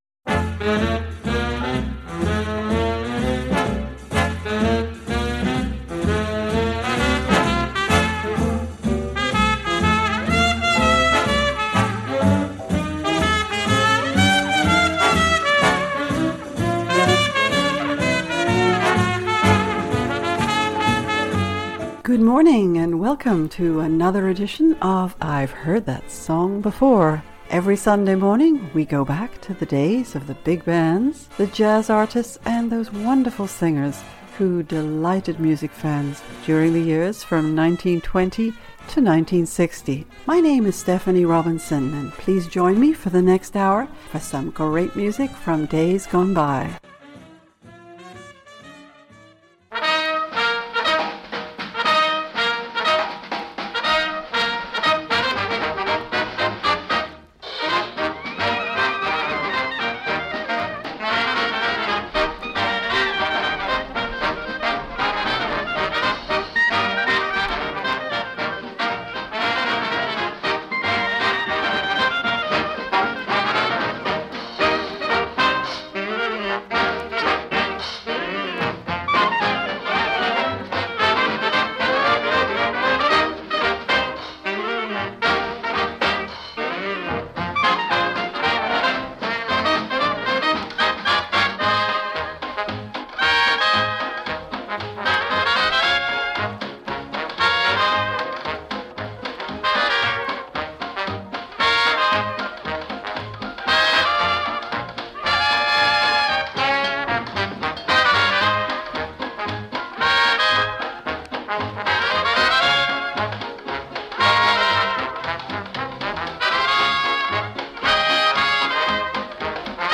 small jazz groups
big bands